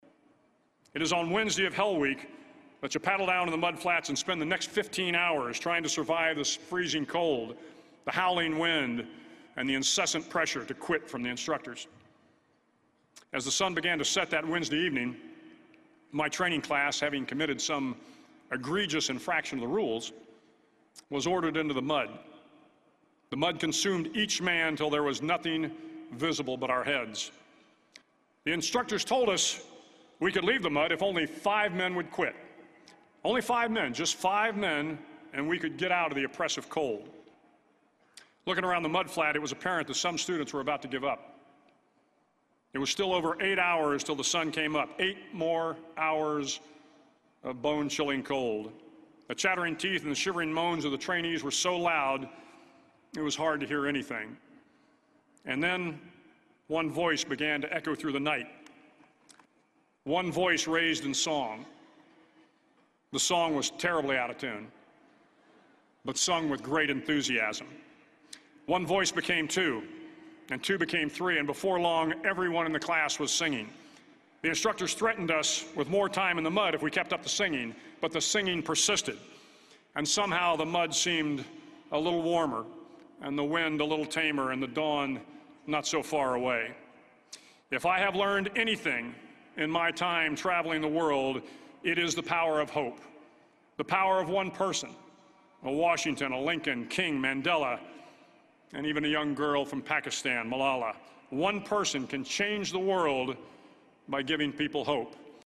公众人物毕业演讲 第239期:威廉麦克雷文2014德州大学演讲(11) 听力文件下载—在线英语听力室